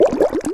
Sfx Boat Through Water Sound Effect
sfx-boat-through-water.mp3